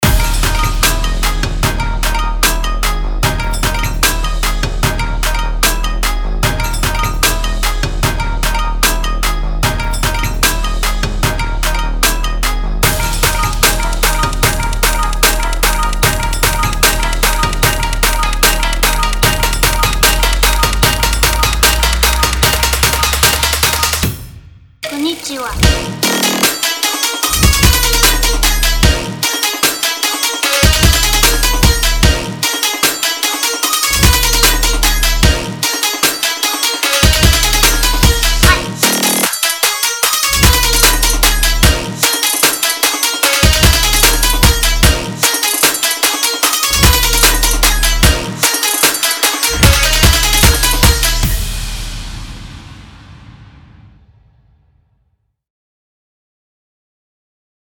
·5个踩Hat
·5个Taiko循环·5个填充
·6个冲击和竖纹
·4个特殊FX